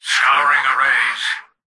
"Scouring arrays" excerpt of the reversed speech found in the Halo 3 Terminals.